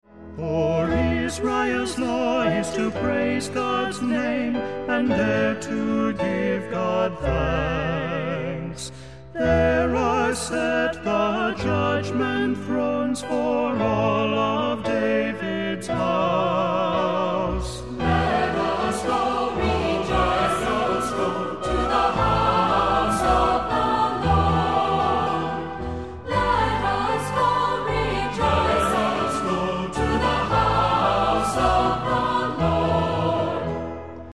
Voicing: SAB,Assembly